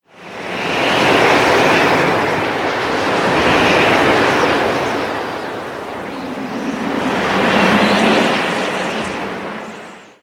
Звуки ветра. Sounds of wind.
Звук завывание снежной бури.